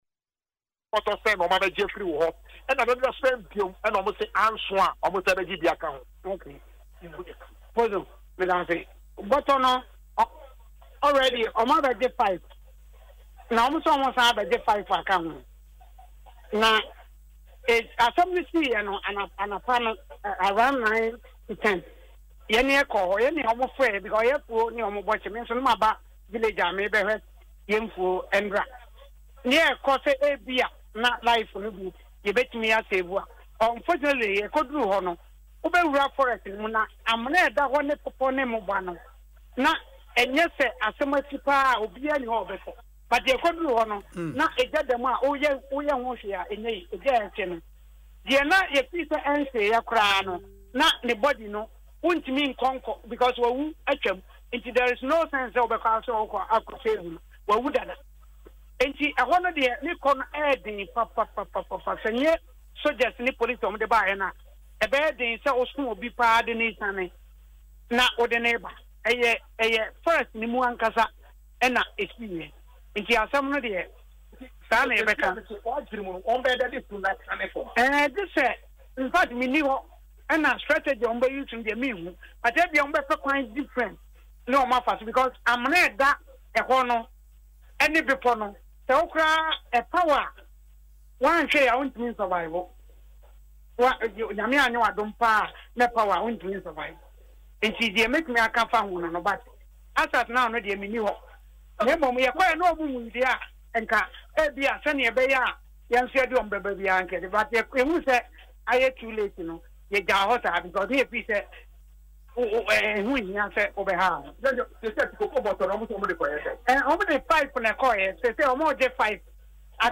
Adansi-crash-eyewitness-.mp3